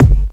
INSKICK20 -L.wav